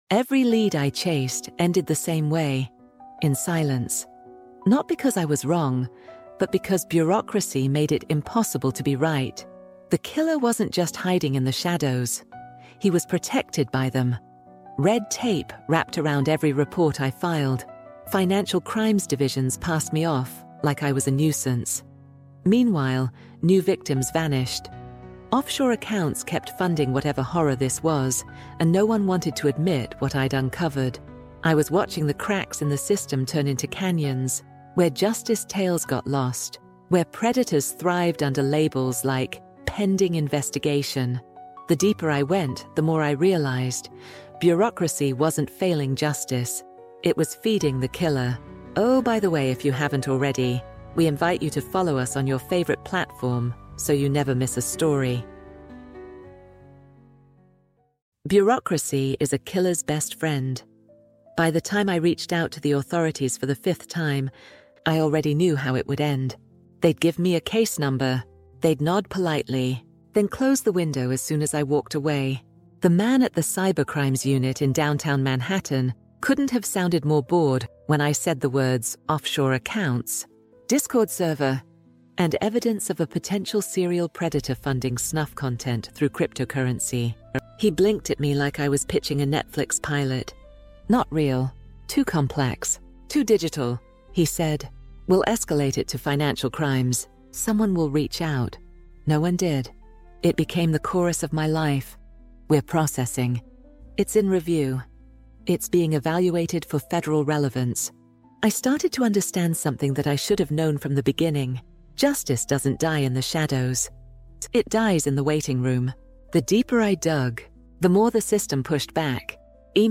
True Crime | Shadows of Justice EP3 | Bureaucracy is a Killer’s Best Friend Audiobook